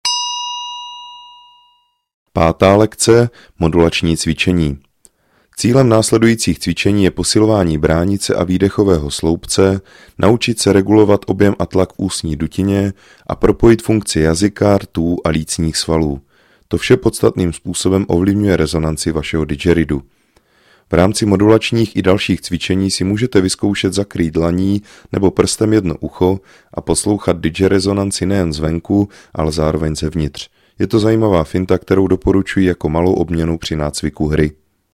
VÝUKA HRY NA DIDGERIDOO I.
Didgeridoo je nástroj, jehož hluboký tón dokáže ukotvit pozornost v přítomném okamžiku.
Track 17 - 5 lekce - Modulacni cviceni.mp3